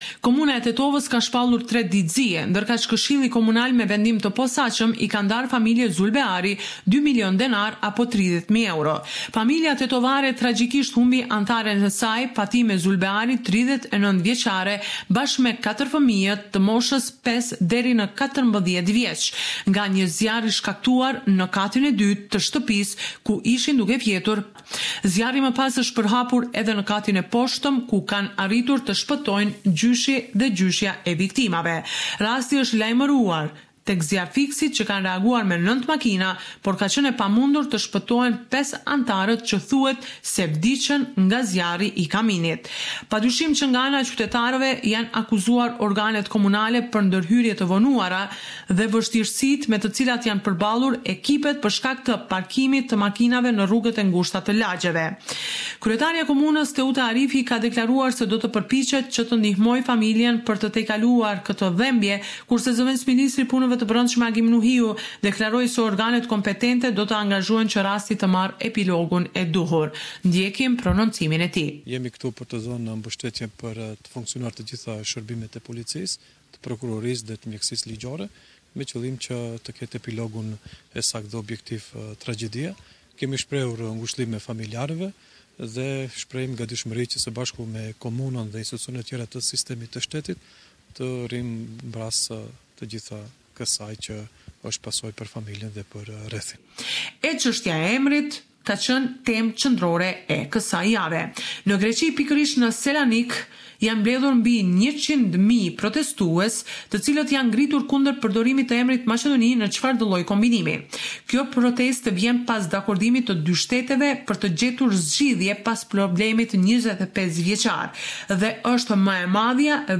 The weekly report with the latest developments in Macedonia.